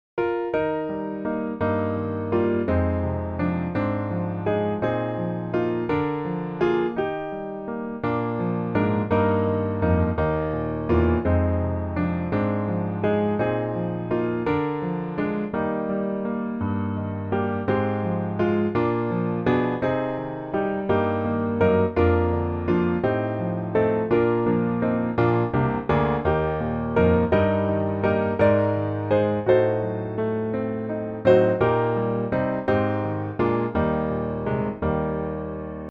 Piano Hymns
Eb Majeur